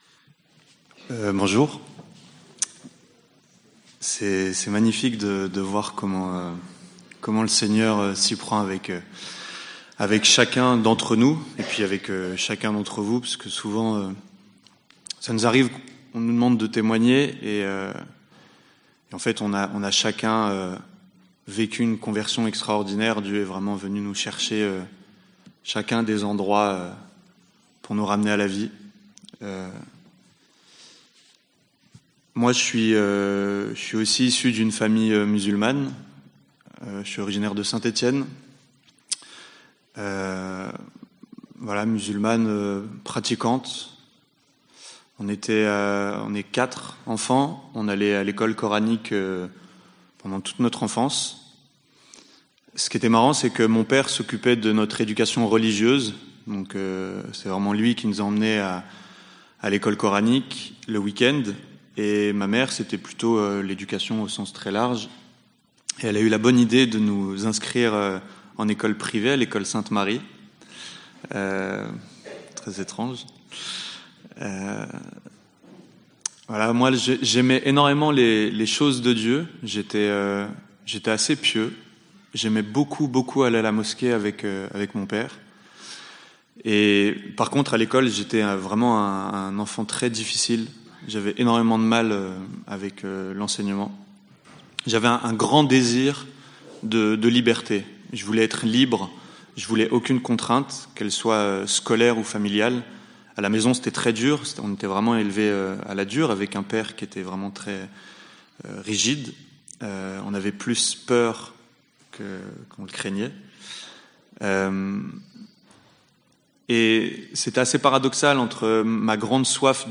Forum des 26-27 mai 2018 - PARIS